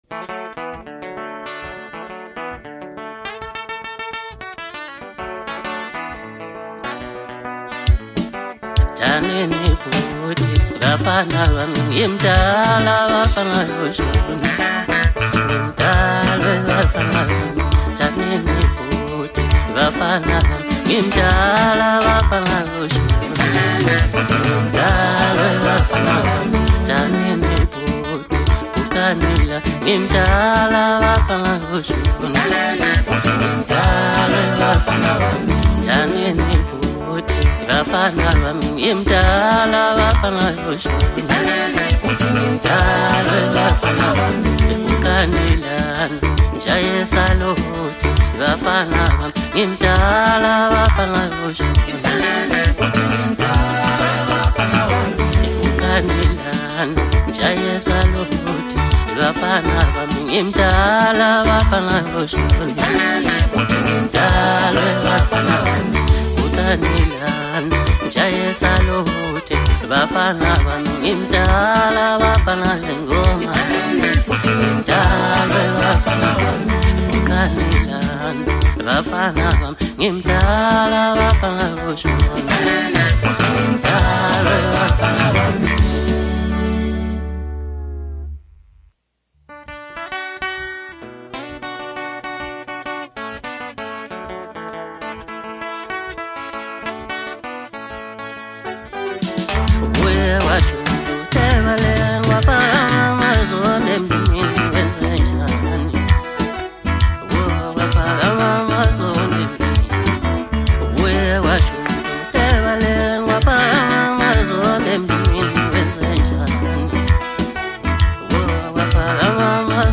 Home » Hip Hop » Latest Mix » Maskandi